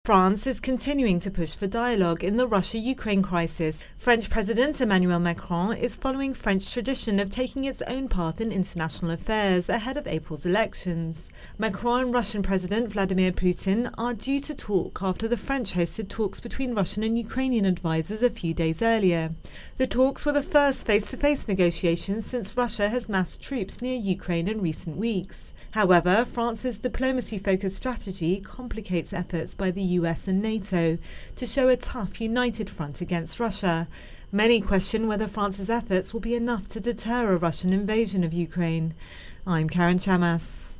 Ukraine Tensions France's Voice Intro and Voicer